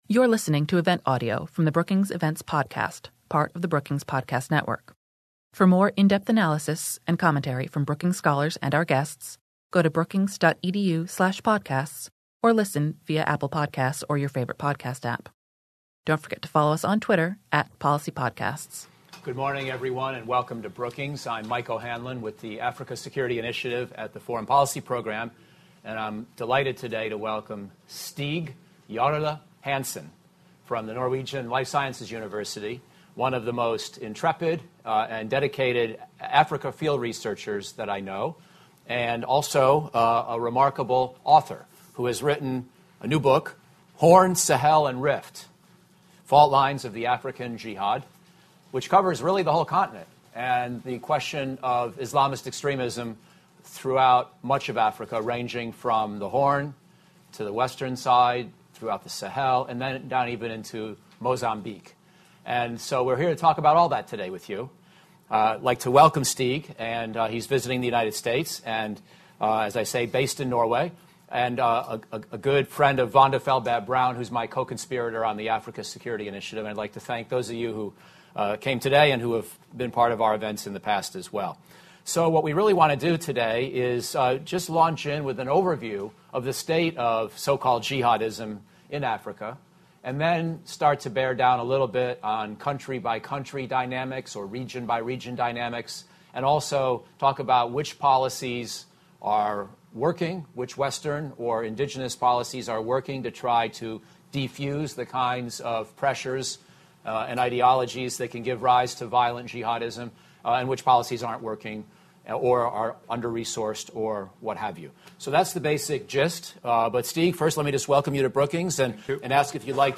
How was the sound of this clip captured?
On November 5, the Africa Security Initiative at the Brookings Institution host an event to discuss jihadi movements in sub-Saharan Africa and their implication for security on the African continent.